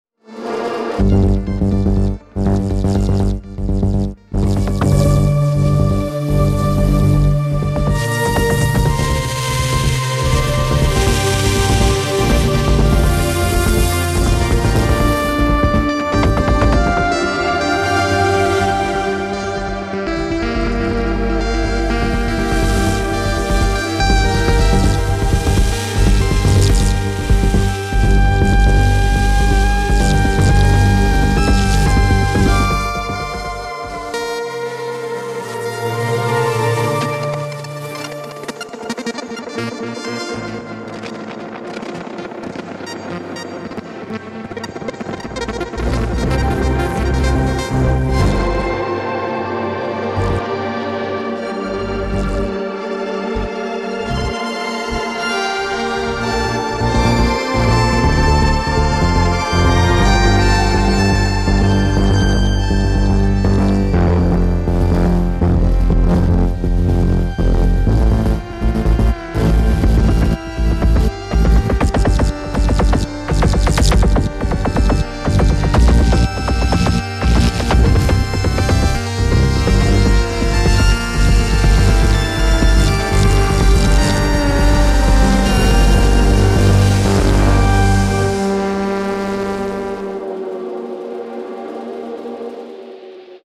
3. 合成器
Sound Dust 承诺，这些音色非常适合演奏丰富、茂密和粗糙的垫音，深沉、非常深沉的不粘稠的低音，以及明亮的琴键和拨弦音色。
- 4 种经典合成器音色，包括 Waldorf Microwave XT、Oberheim Xpander、Korg MS20 和 Arturia Microphreak。
- 背景噪音，可以选择唱片噪音或磁带噪音。
- 磁带和胶水控制，可以将有机、肮脏的整体感融入到所有声音中。